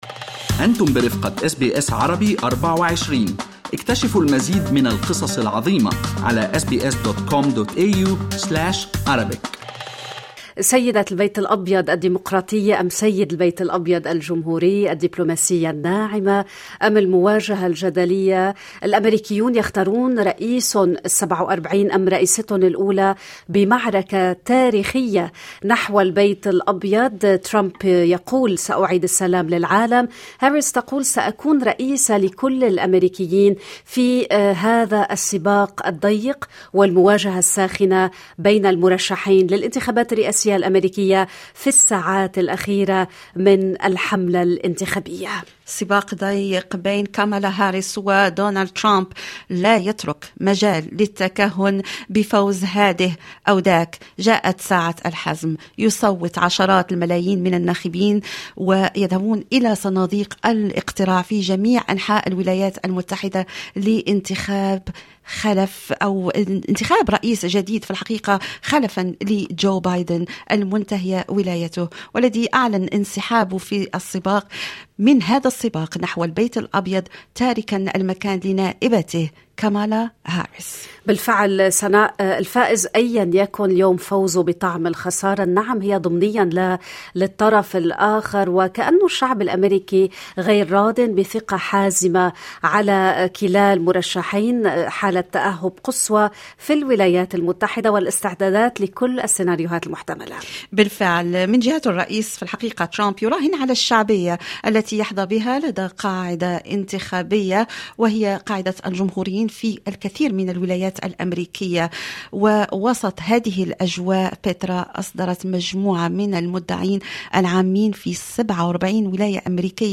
محلل سياسي أمريكي: إيران تحاول التدخل في سير الإنتخابات الأمريكية